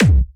b_kick_v127l4o5c.ogg